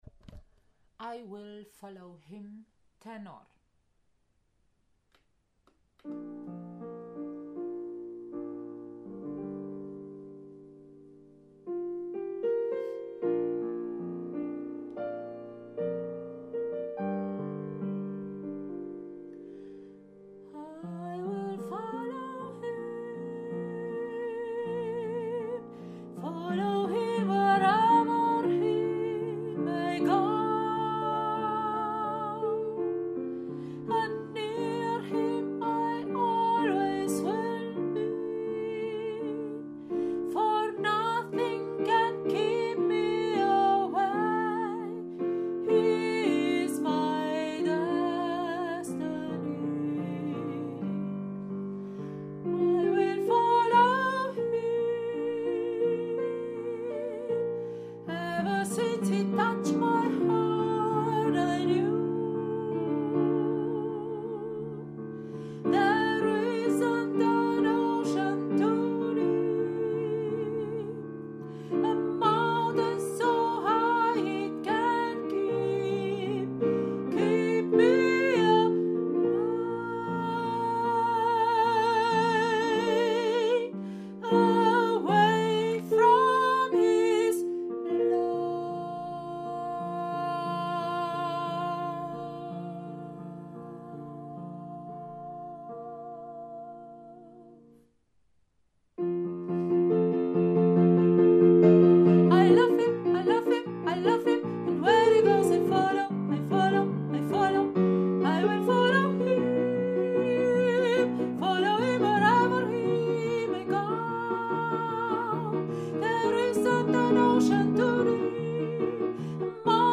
I will follow him – Tenor